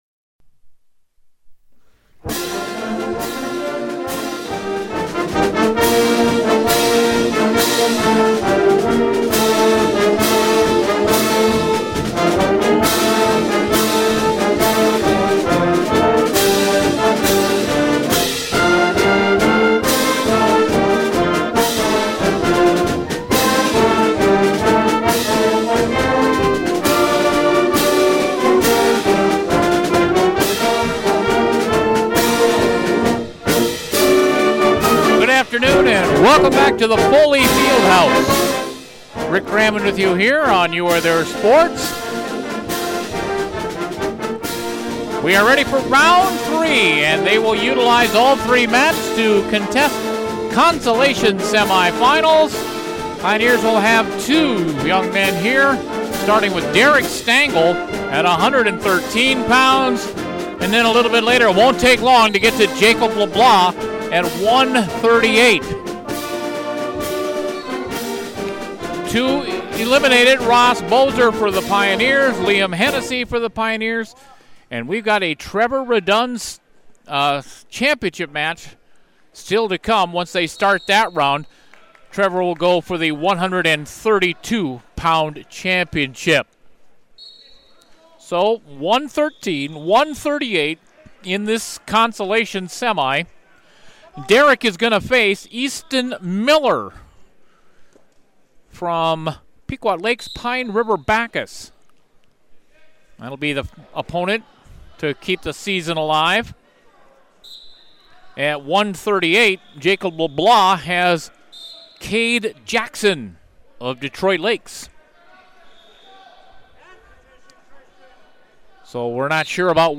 This is Part 3 of the Pioneers day at the State Individual Prelims at the Foley Field House.